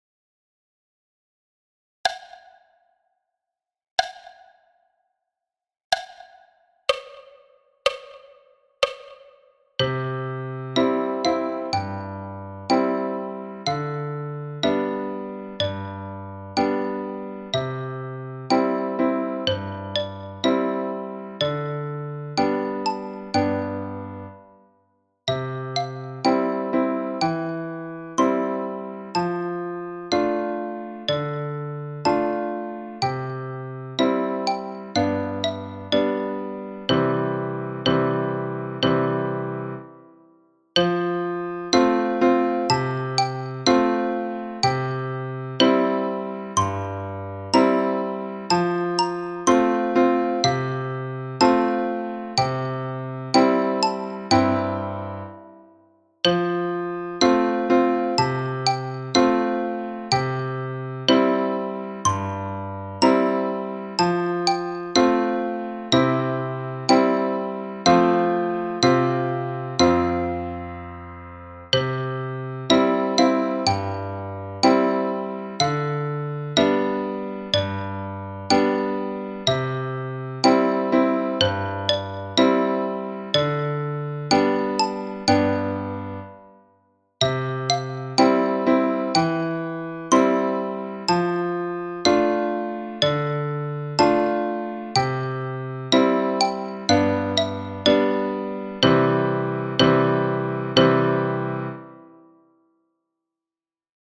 Danse du canard – tutti à 62 bpm